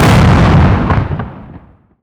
EXPLOSION_Distorted_10_Long_stereo.wav